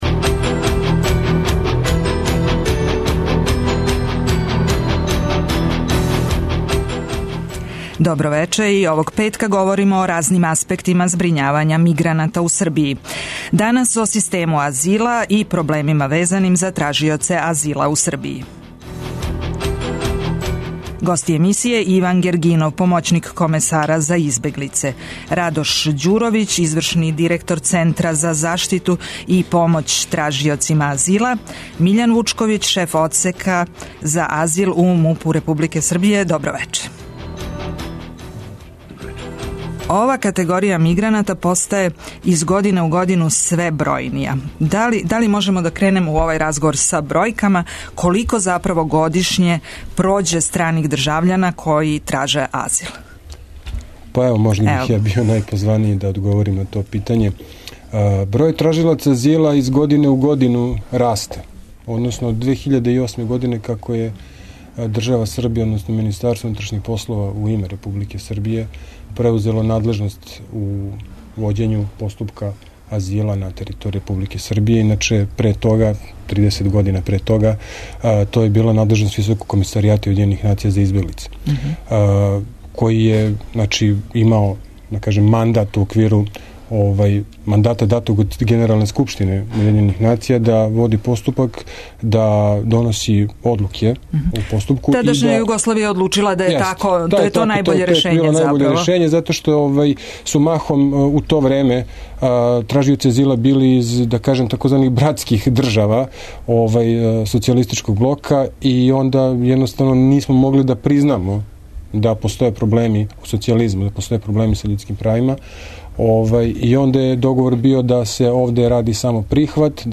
У емисији ћемо емитовати и репортажу из Центра за малолетне стране држављане који је смештен у Заводу за васпитање деце и омладине Београд "Васа Стајић", где тренутно борави неколико дечака из Сирије и Авганистана.
преузми : 24.82 MB У средишту пажње Autor: Редакција магазинског програма Свакога радног дана од 17 часова емисија "У средишту пажње" доноси интервју са нашим најбољим аналитичарима и коментаторима, политичарима и експертима, друштвеним иноваторима и другим познатим личностима, или личностима које ће убрзо постати познате.